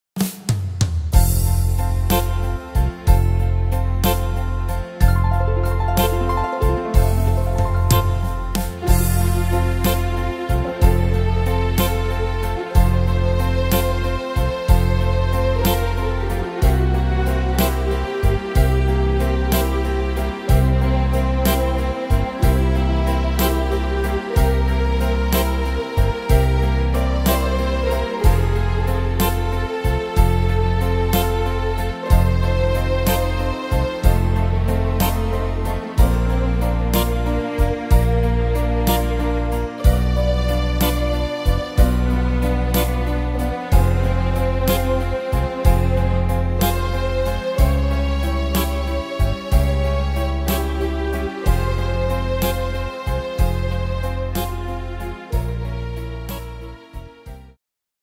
Tempo: 62 / Tonart: F-Dur